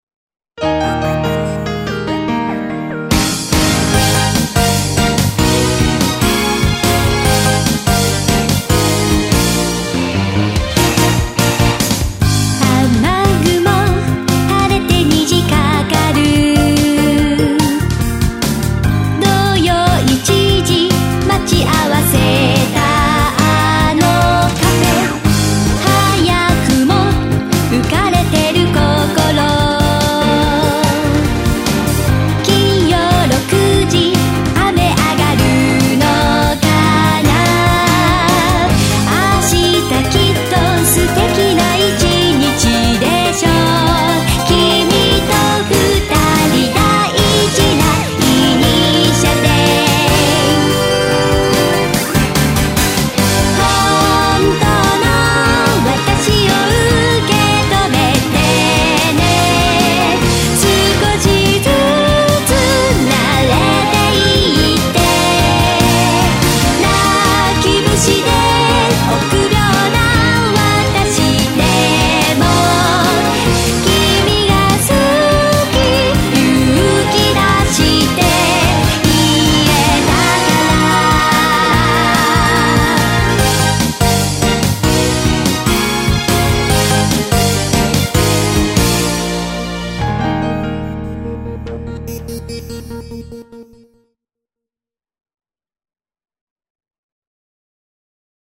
アニメのエンディングテーマ風の90年代を想起させられるアレンジです。